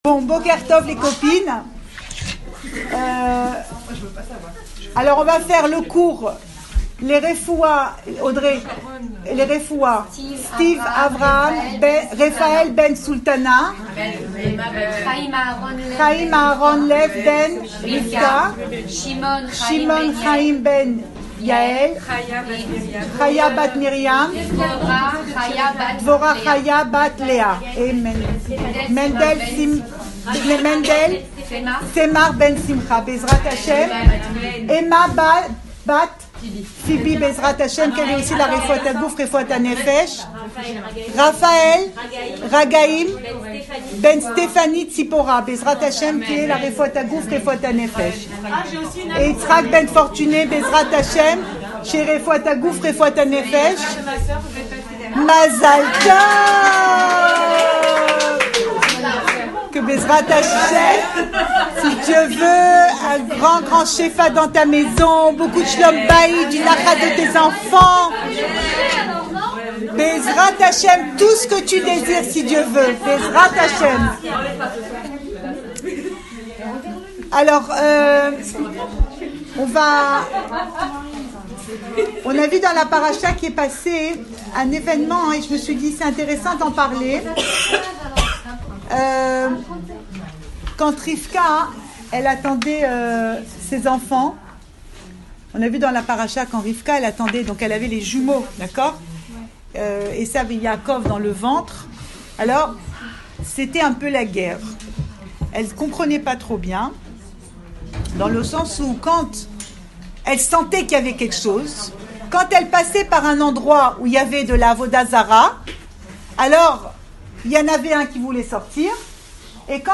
Cours audio Fêtes Le coin des femmes Pensée Breslev - 21 novembre 2017 29 novembre 2017 Le Chalom Bayit chez nos Patriarches… Enregistré à Raanana